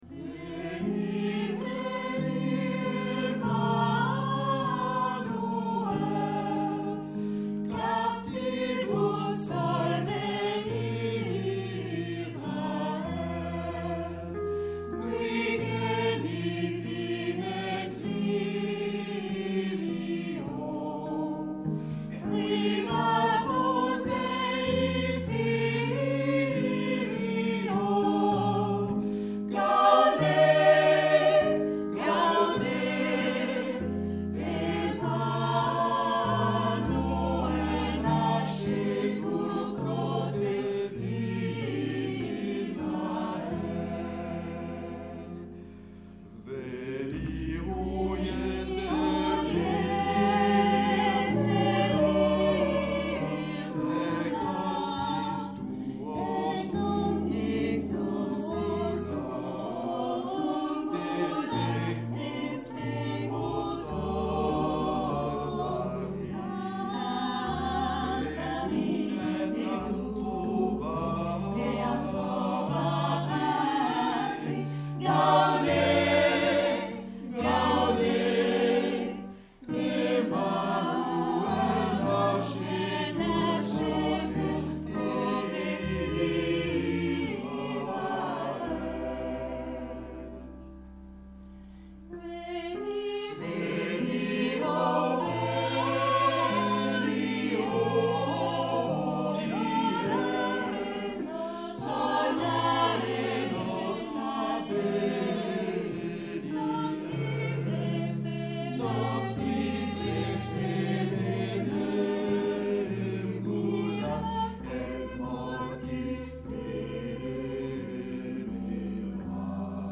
SAB ODER SATB (4 gemischter Chor Stimmen) ; Instrumentalstimmen.
Motette.
Hymnus (geistlich).